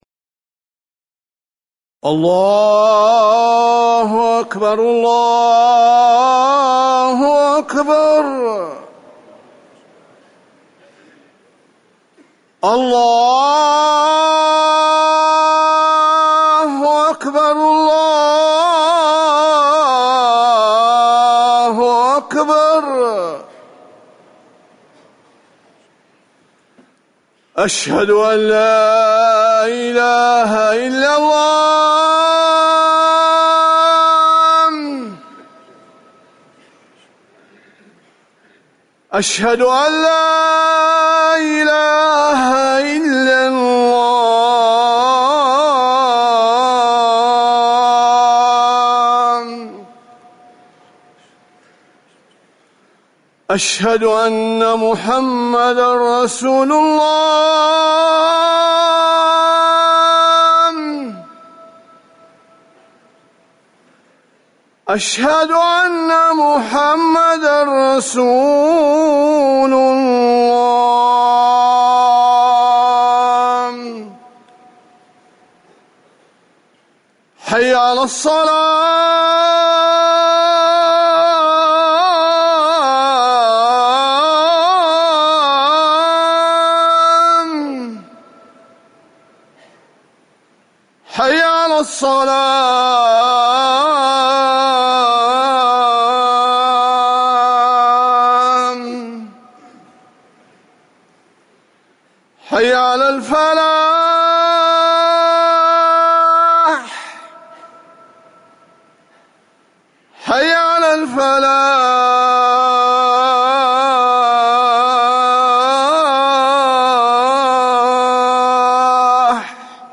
أذان الجمعة الثاني - الموقع الرسمي لرئاسة الشؤون الدينية بالمسجد النبوي والمسجد الحرام
تاريخ النشر ٧ محرم ١٤٤١ هـ المكان: المسجد النبوي الشيخ